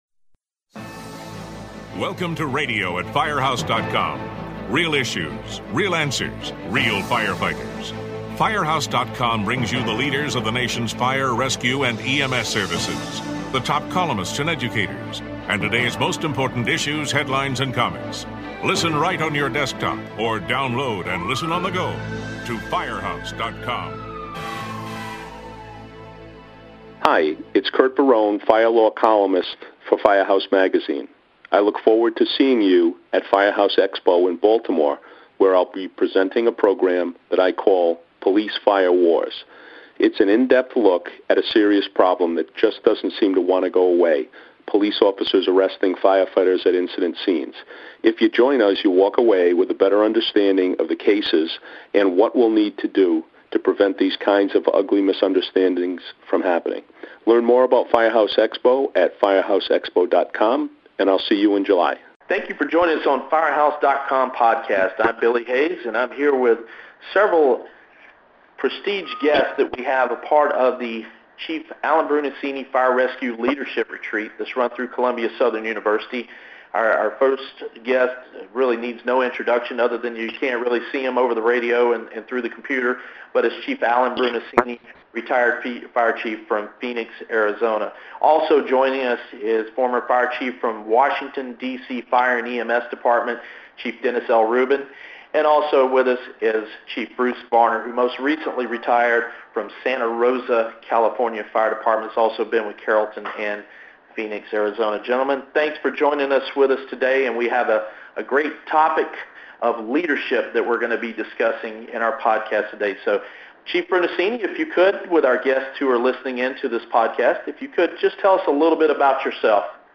A group of veteran fire officers discuss some of the issues that are impacting the fire service and creating extraordinary challenges for fire department leaders.